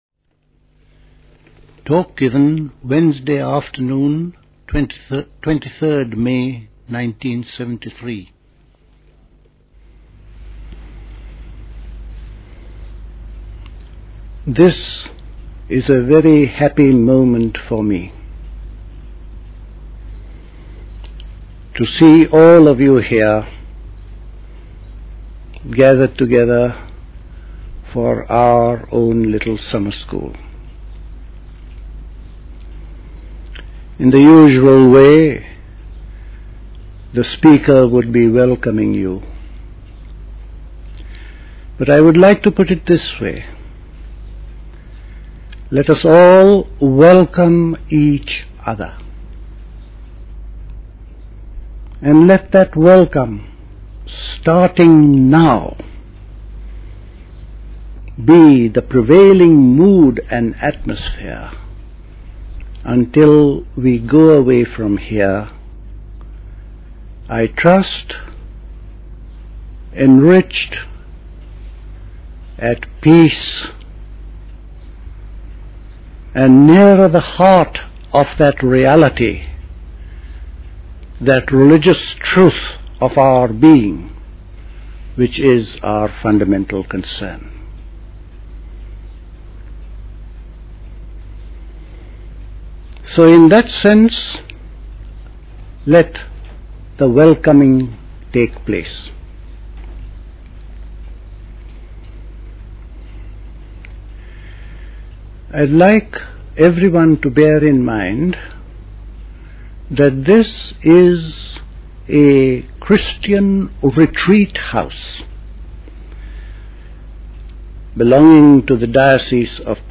Recorded at the 1973 Catherington House Summer School.